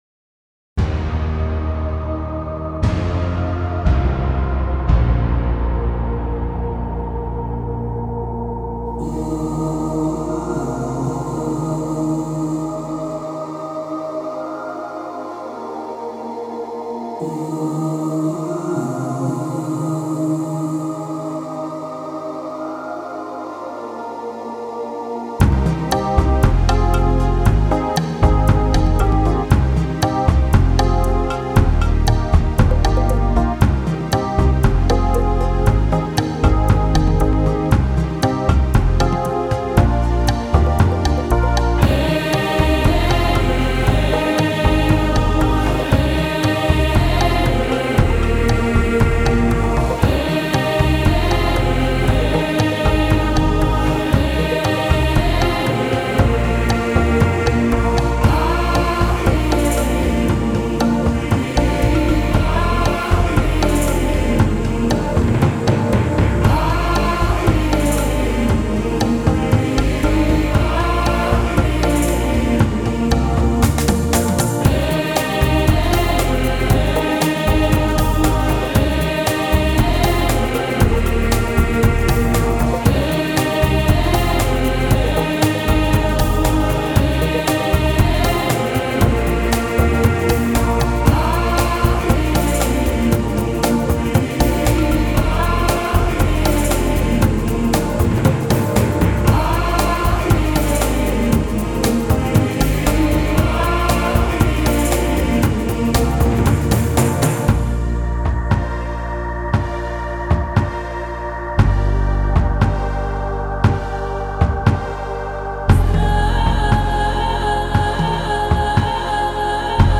音乐流派；新世纪音乐 (New Age) / 环境音乐 (Ambient Music)
歌曲风格：轻音乐 (Easy Listening) / 纯音乐 (Pure Music)
新世纪发烧EQ音乐 旋律优美·舒缓神经·灵性创作